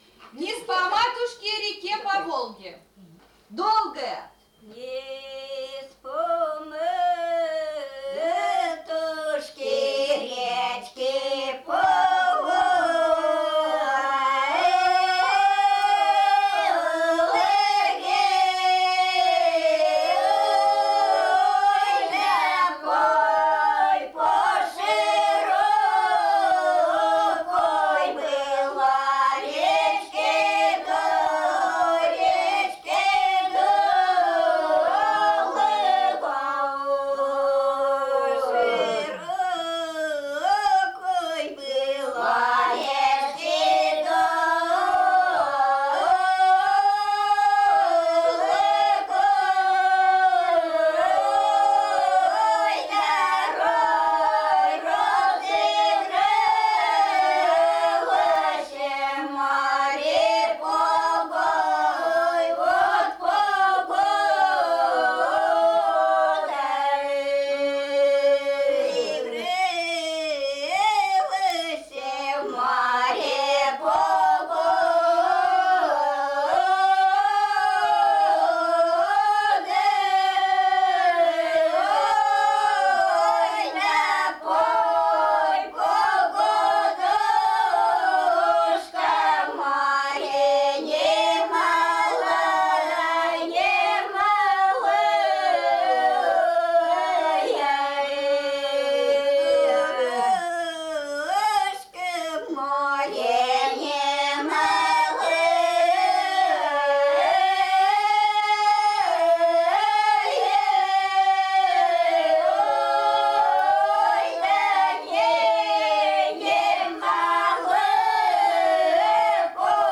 Этнос: РусскиеКонфессия: ПравославиеЯзык: Диалект северного наречия русского языка
Лирические песни на Мезени записаны преимущественно в исполнении женских коллективов.
Исполнялись лирические песни в плотной гетерофонной фактуре. На Мезени протяжные песни было принято петь одновременно в разных регистрах — тонкими (высокими) и толстыми (низкими) голосами.
01 Лирическая песня «Вниз по матушке по Волге» в исполнении жительниц с. Ценогора Лешуконского р-на Архангельской обл.